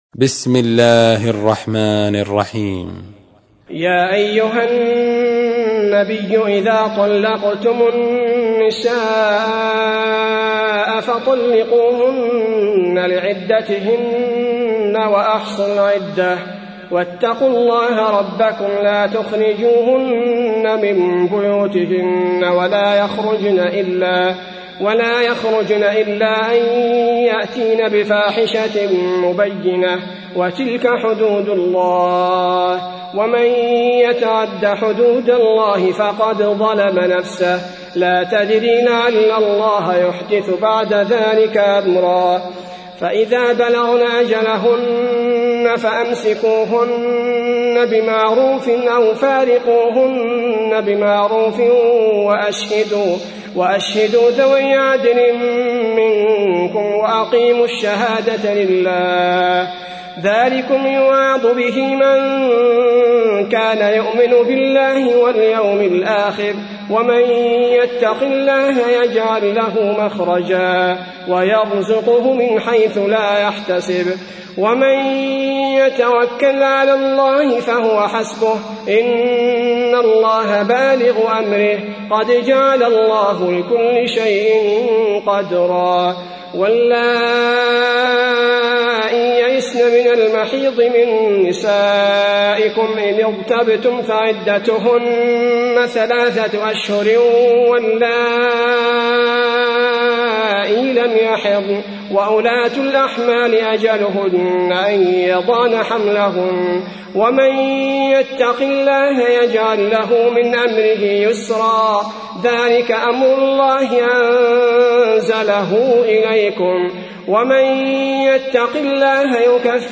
تحميل : 65. سورة الطلاق / القارئ عبد البارئ الثبيتي / القرآن الكريم / موقع يا حسين